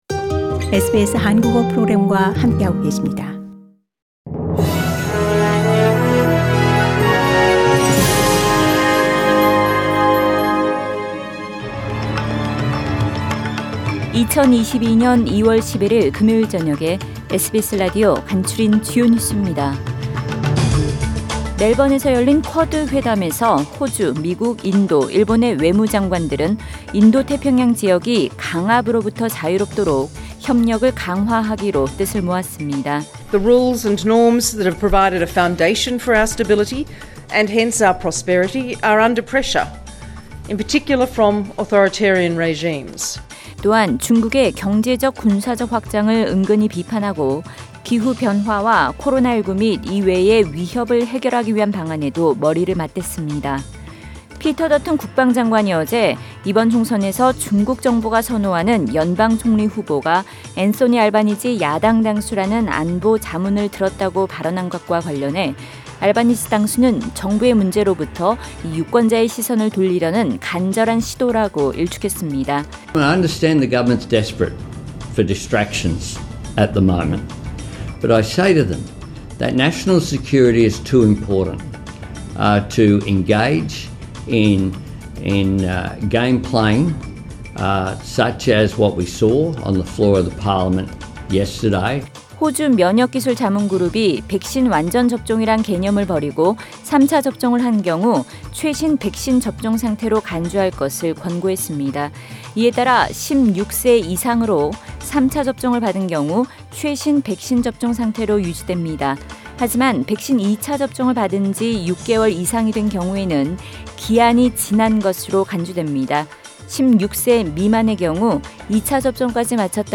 SBS News Outlines…2022년 2월 11일 저녁 주요 뉴스